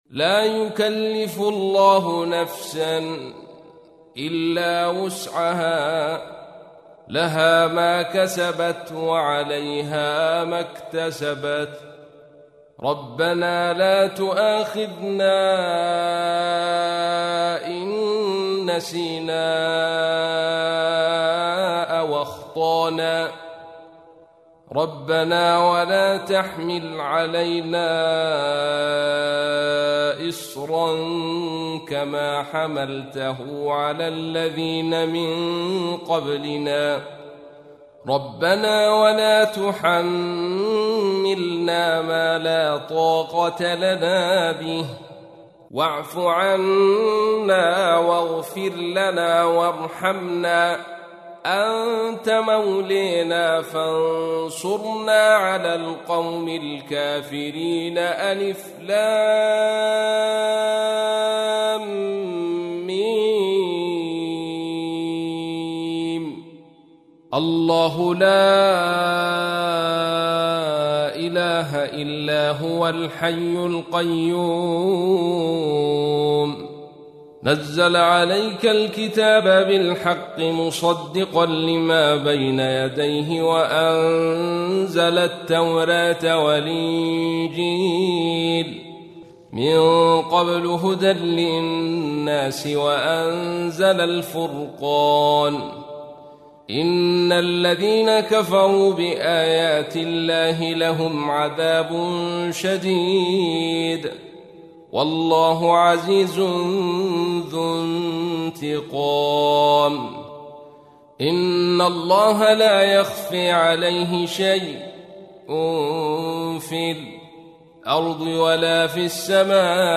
تحميل : 3. سورة آل عمران / القارئ عبد الرشيد صوفي / القرآن الكريم / موقع يا حسين